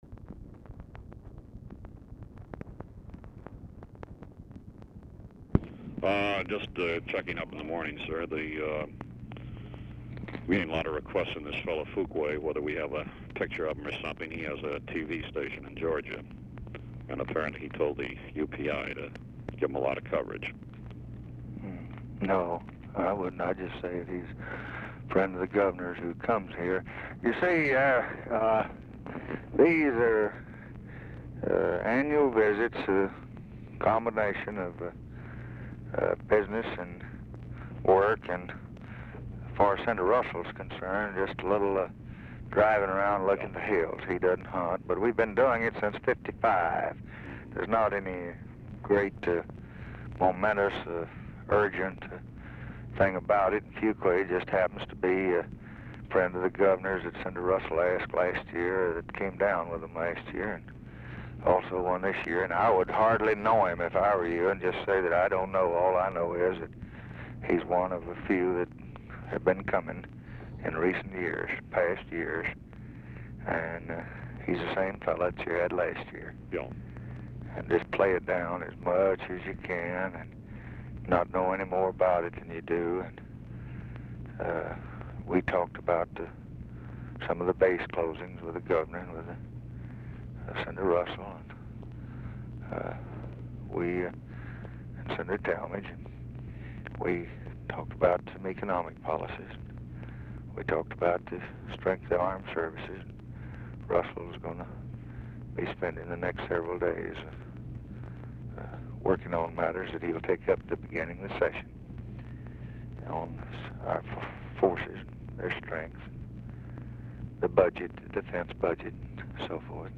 OFFICE CONVERSATION DURING CALL
Format Dictation belt
Location Of Speaker 1 LBJ Ranch, near Stonewall, Texas
Specific Item Type Telephone conversation